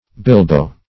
Bilbo \Bil"bo\, n.; pl. Bilboes.